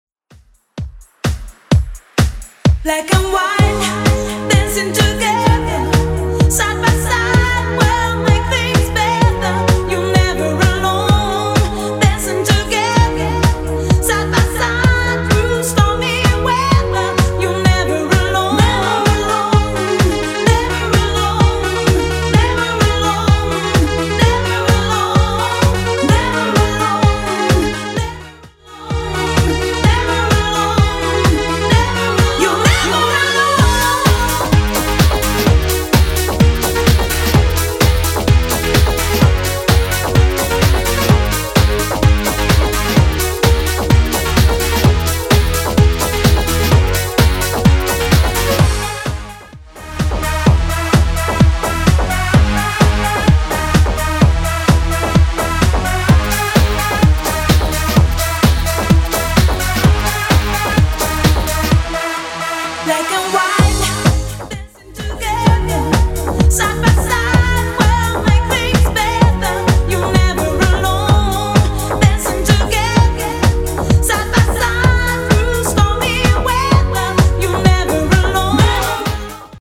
Genre: BASS HOUSE
BPM: 128